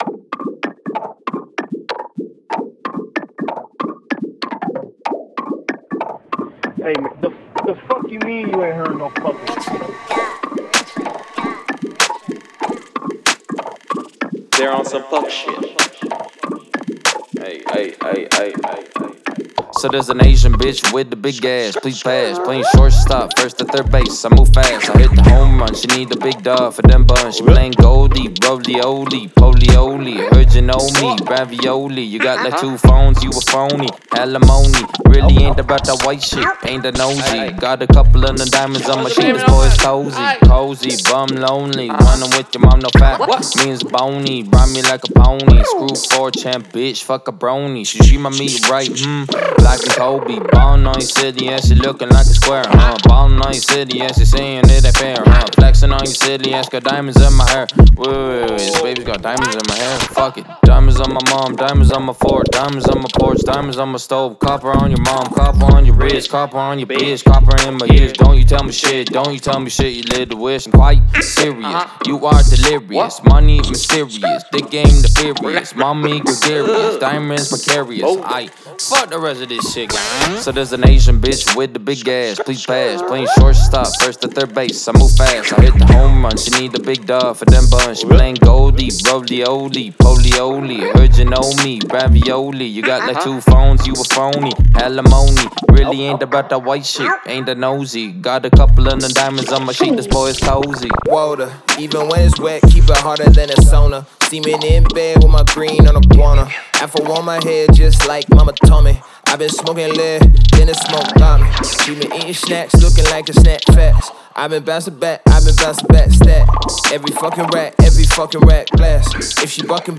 это энергичная и зажигательная трек в жанре хип-хоп